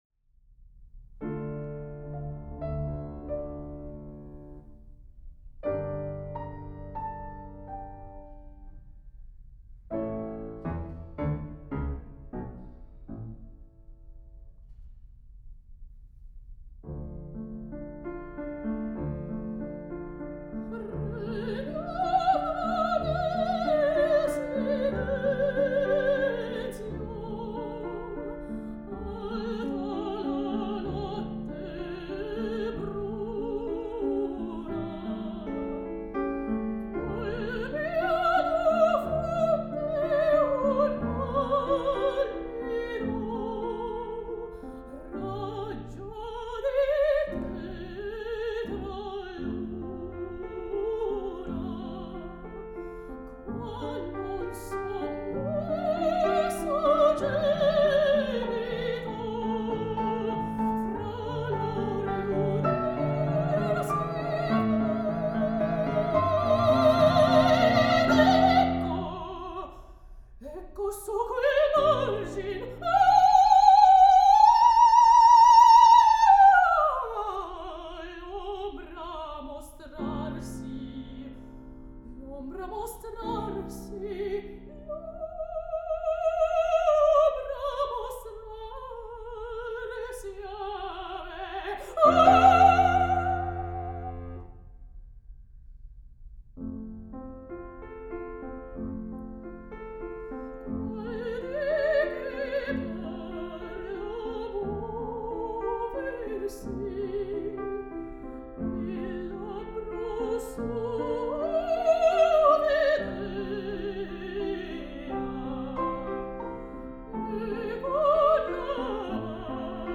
Air